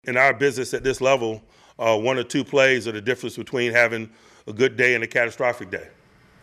Tomlin held his weekly news conference yesterday and said he’s hoping for a better effort all around this week as the Steelers begin a crucial three-game set against some of the top teams in football.